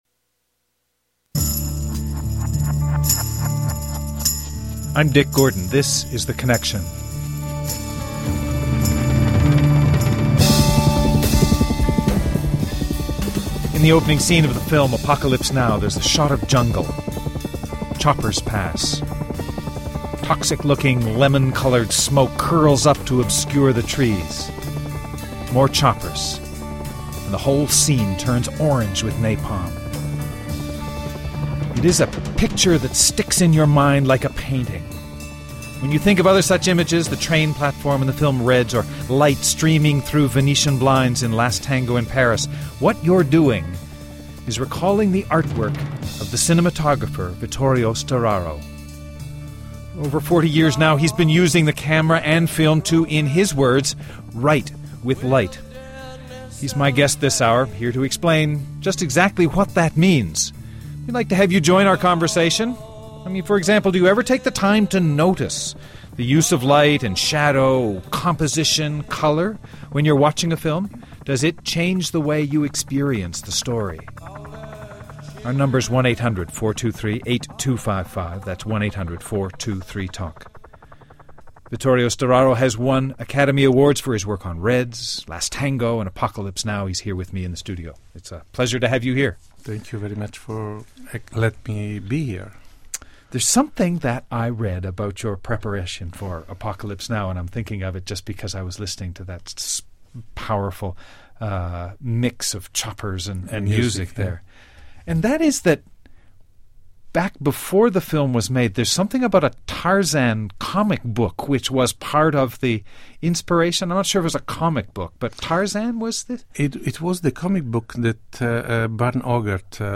We talk about medicine, and morality with Doctor Paul Farmer.